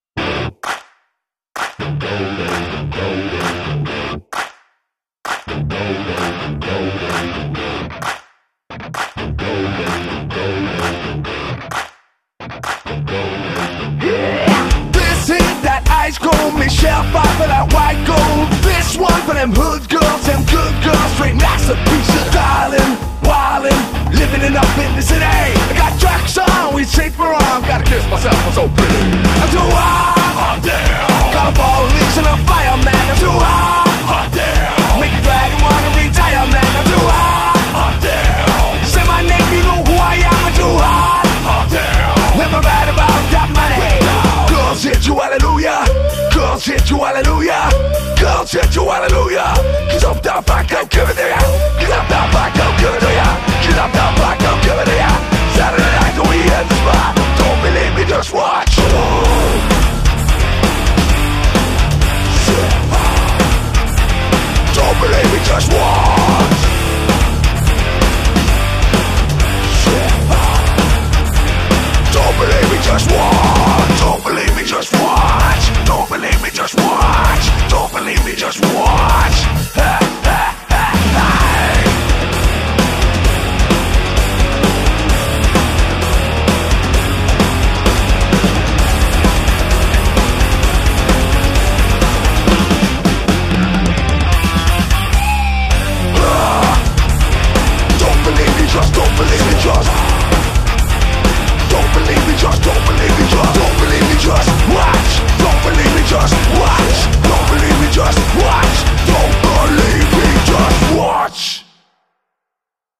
BPM130
MP3 QualityMusic Cut